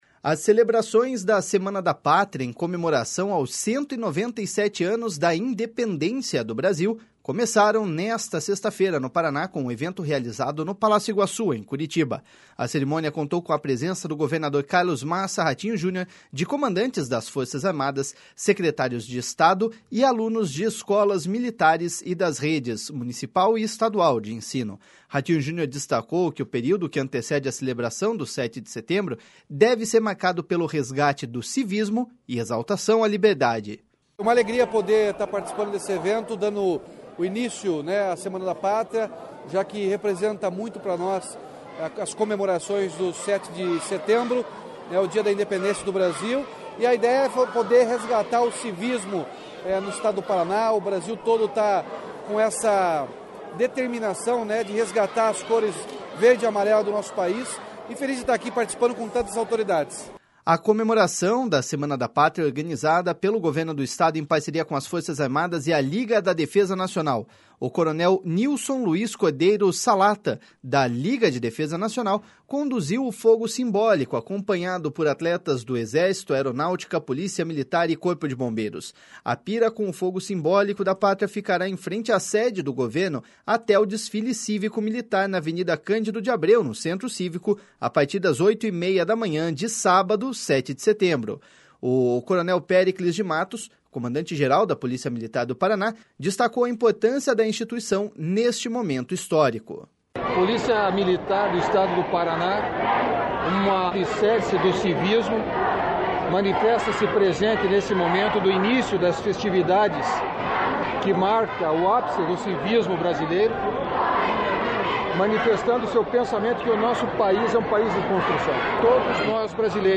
Ratinho Junior destacou que o período que antecede a celebração do 7 de Setembro deve ser marcado pelo resgate do civismo e exaltação à liberdade.// SONORA RATINHO JUNIOR.//
O Coronel Péricles de Matos, comandante-geral da Polícia Militar do Paraná, destacou a importância da instituição neste momento histórico.// SONORA CORONEL PÉRICLES.//